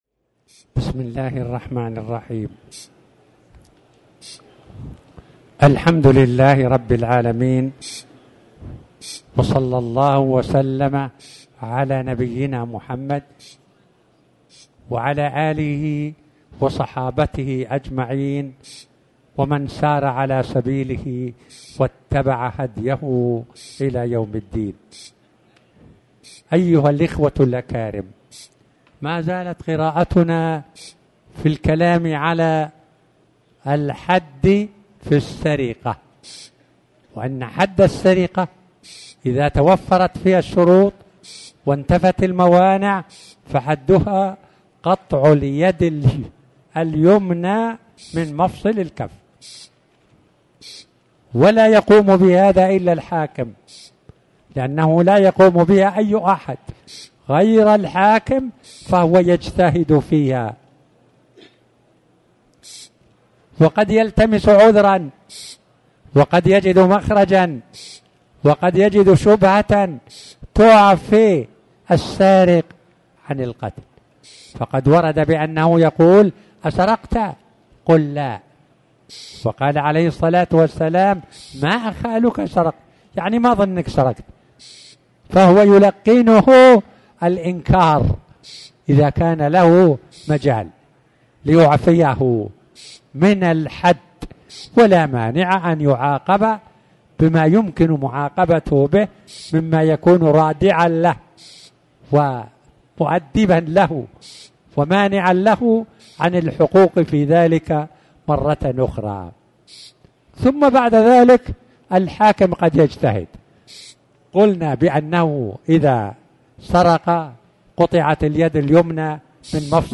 تاريخ النشر ٦ ربيع الأول ١٤٤٠ هـ المكان: المسجد الحرام الشيخ